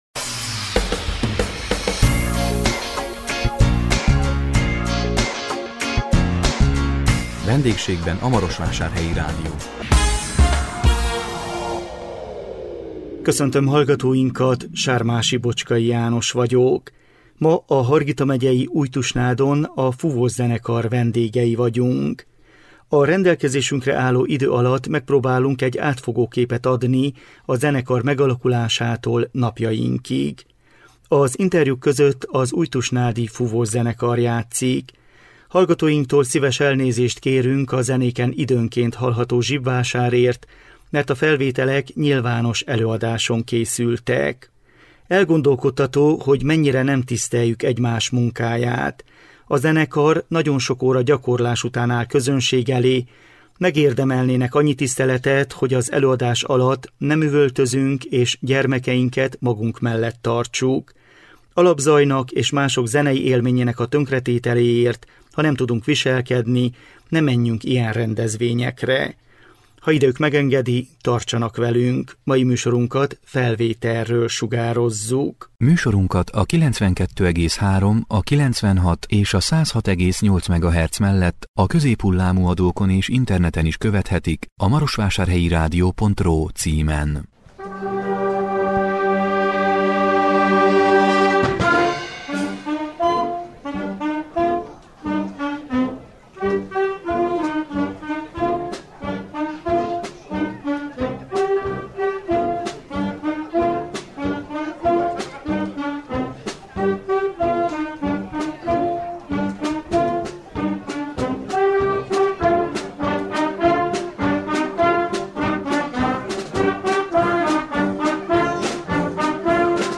A 2022 október 6-án jelentkező VENDÉGSÉGBEN A MAROSVÁSÁRHELYI RÁDIÓ című műsorunkban Újtusnádról jelentkeztünk, a Fúvószenekar vendégei voltunk. A rendelkezésünkre álló idő alatt megpróbáltunk egy átfogó képet adni a zenekar megalakulásától napjainkig.